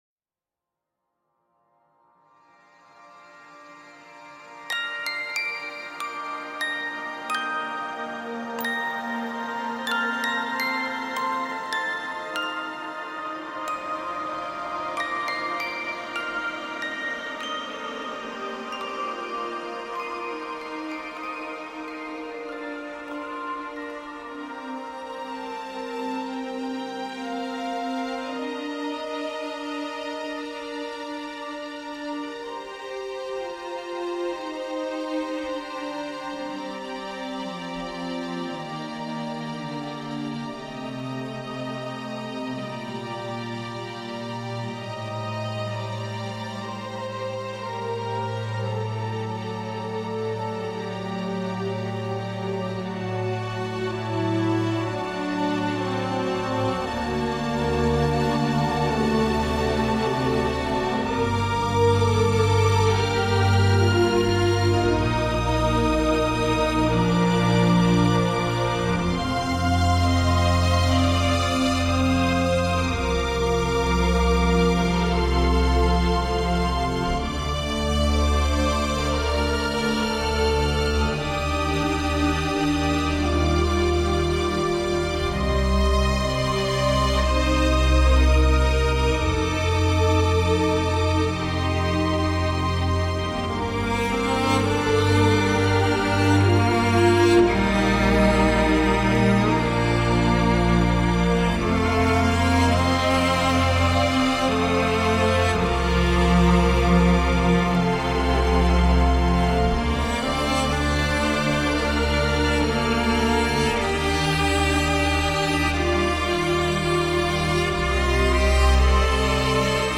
Un score fonctionnel et très oubliable.
Sous le sapin, électro flippant à gogo et cordes lugubres
synthés et jeu de batterie, avec quelques cordes
Anxiogène et très efficace.